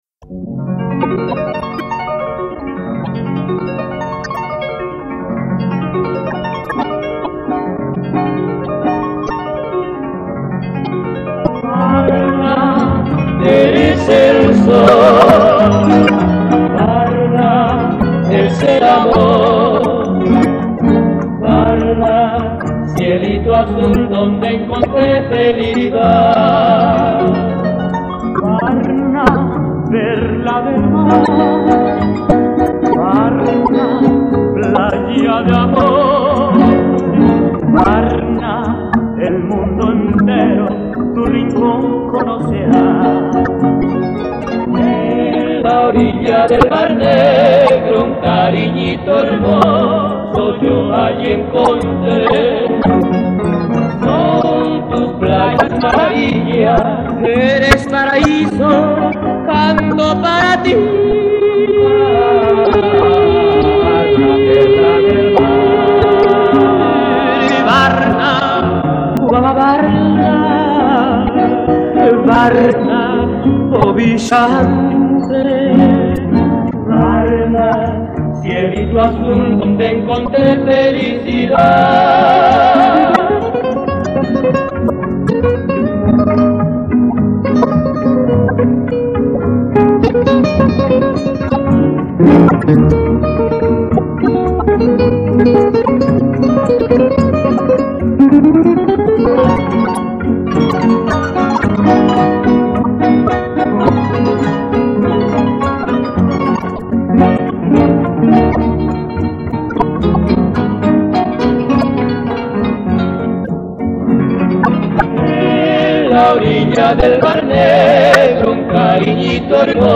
And how beautifully they sing: